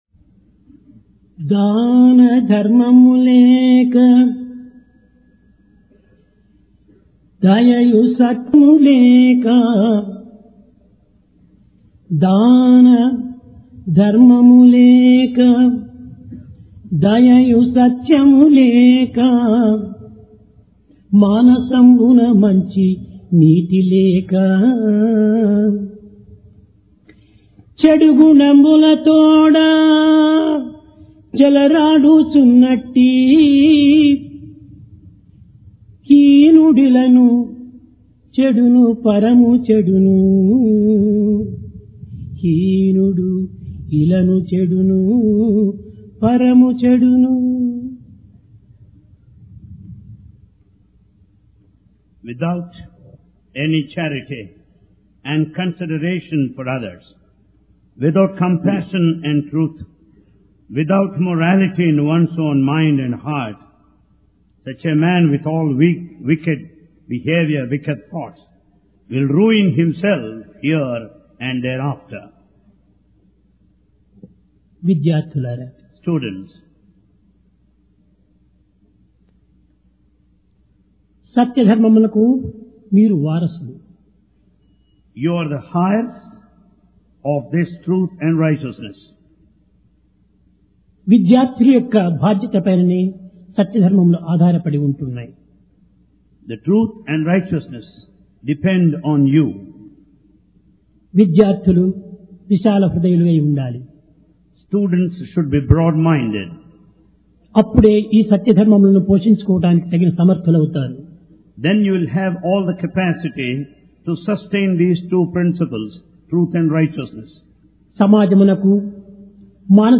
PRASHANTI VAHINI - DIVINE DISCOURSE 11 JULY, 1996
Sai Darshan Home Date: 11 Jul 1996 Occasion: Divine Discourse Place: Prashanti Nilayam Practice - Better Than Precept Life today is highly confused.